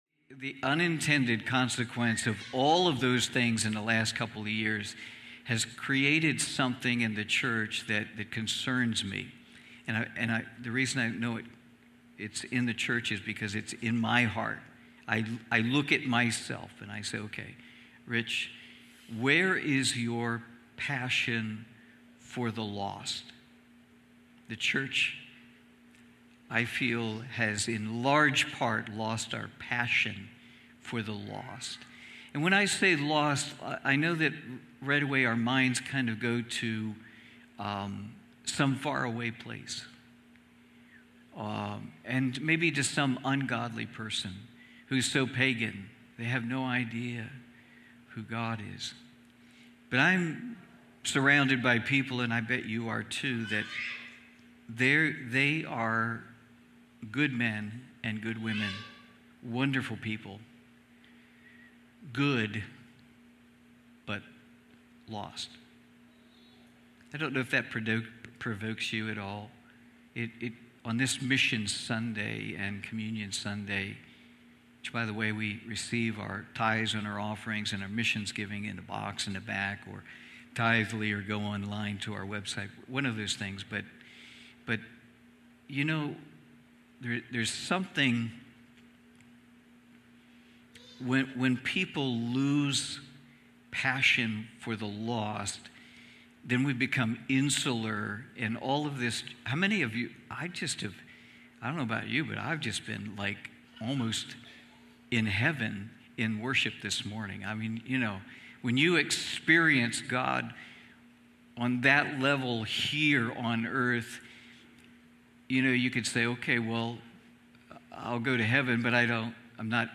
Cornerstone Fellowship Sunday morning service, livestreamed from Wormleysburg, PA.